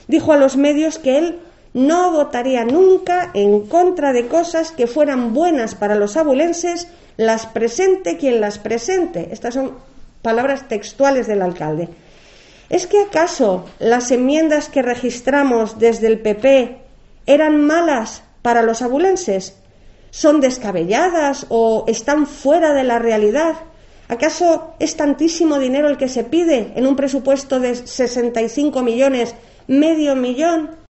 Sonsoles Sánchez-Reyes, portavoz PP. Enmiendas PP rechazadas por XAV